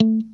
FUNKMUTE1-2.wav